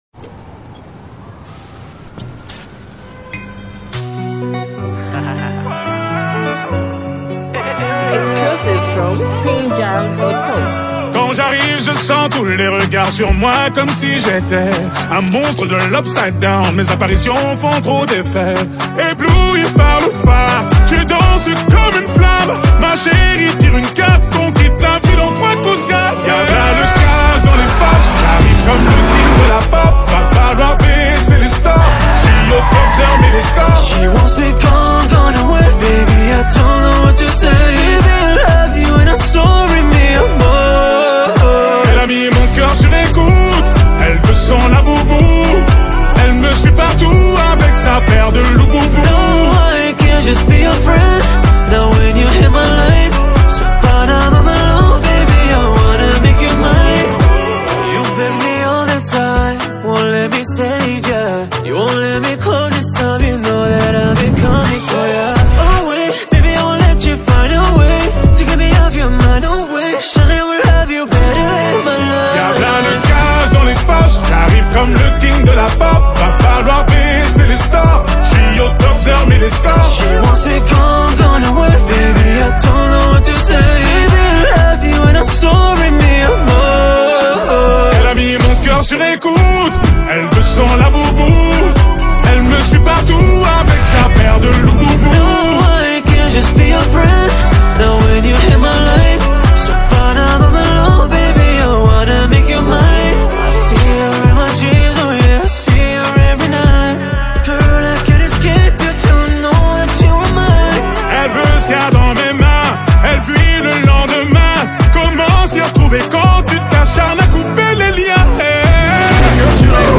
smooth vocals and a memorable hook